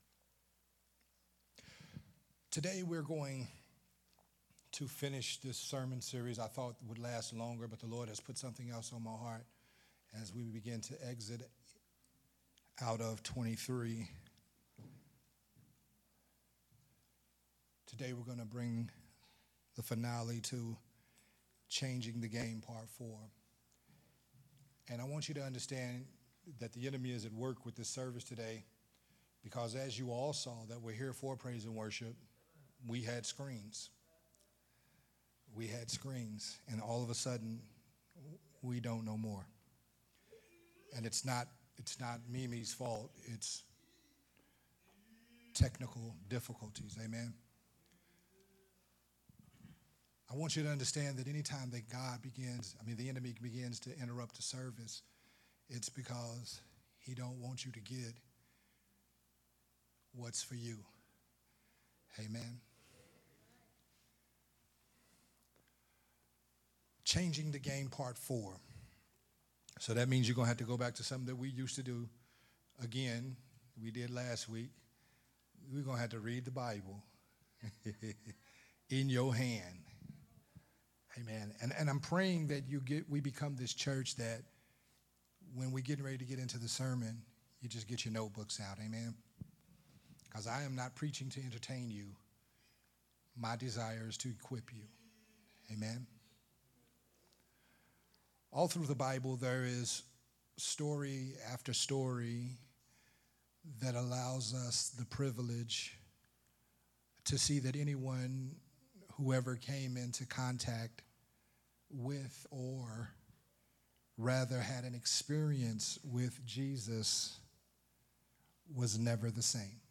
of the sermon series “Changing the Game”
recorded at Unity Worship Center on October 29th